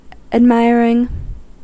Ääntäminen
Ääntäminen US Tuntematon aksentti: IPA : /æd.ˈmaɪ.ɚ.ɪŋ/ Haettu sana löytyi näillä lähdekielillä: englanti Admiring on sanan admire partisiipin preesens.